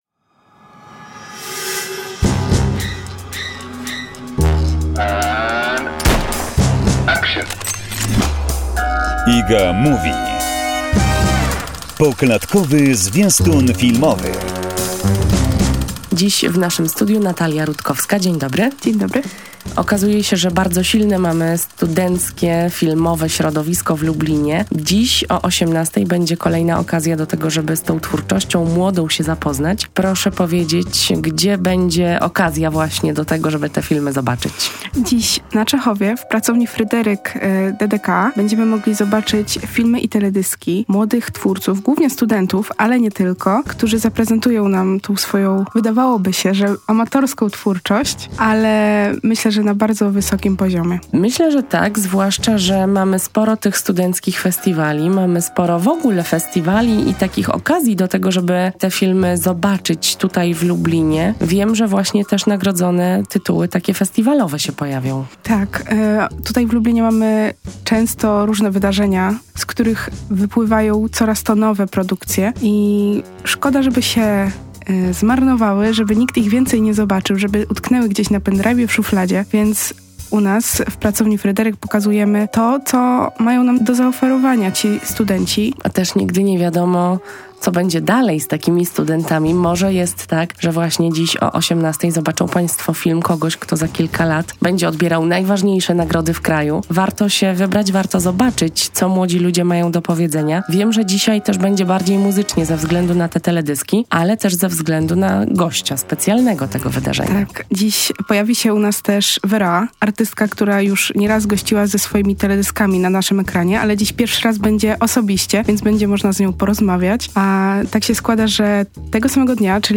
W naszym studiu